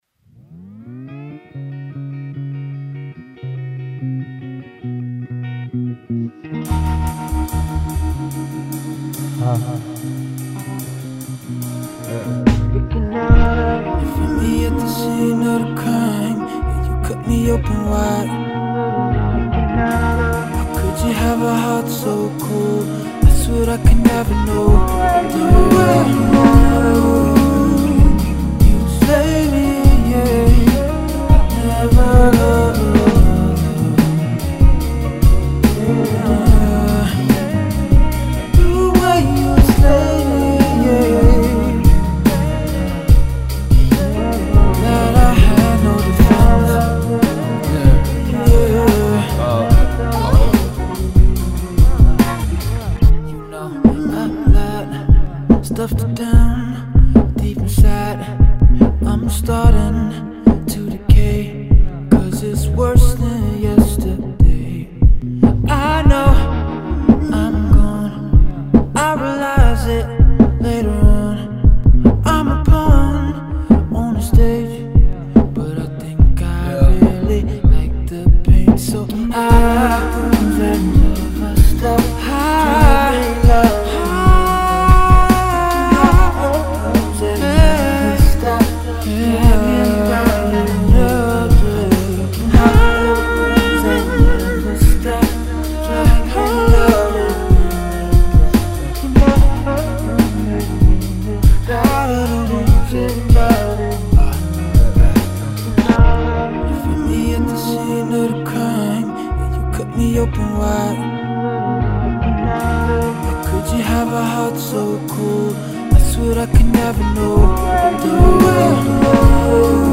he proves he’s a vocal virtuoso on the Jazzy production.